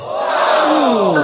GASPING DISSAPOINTED.wav
Original creative-commons licensed sounds for DJ's and music producers, recorded with high quality studio microphones.
gasping_dissapointed_57h.mp3